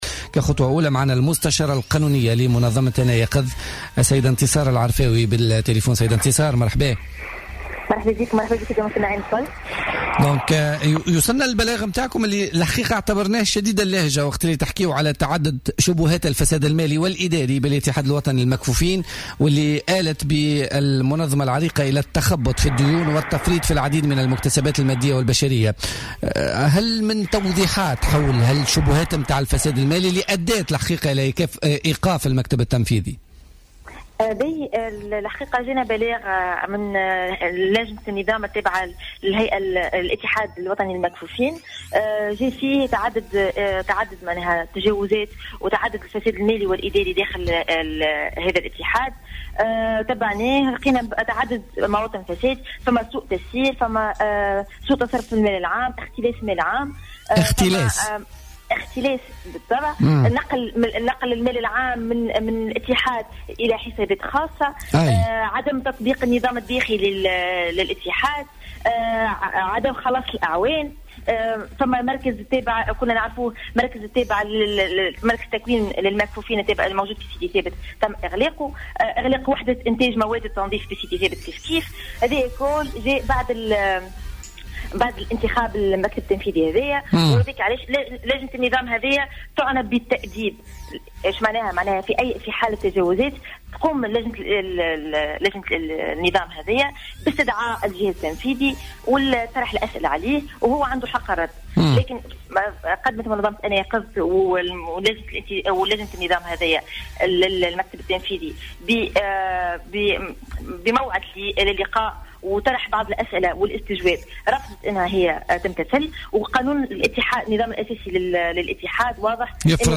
في تصريح للجوهرة أف أم اليوم الجمعة خلال برنامج "الحدث"